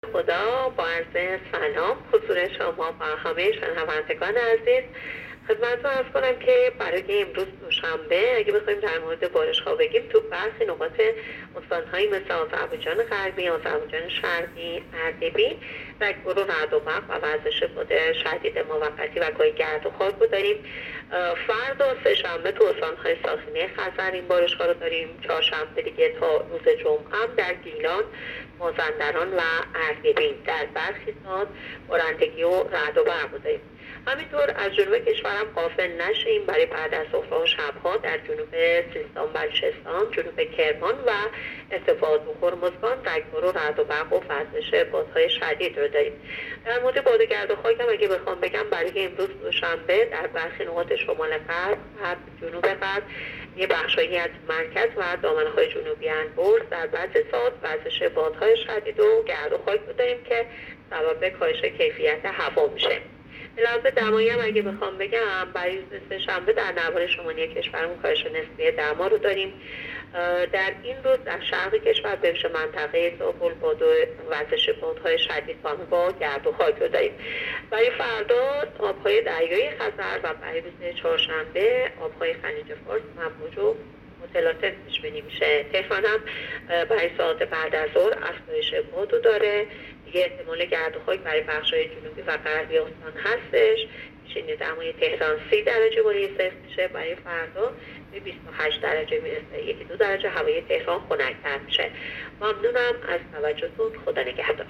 گزارش رادیو اینترنتی پایگاه‌ خبری از آخرین وضعیت آب‌وهوای ۲۱ مهر؛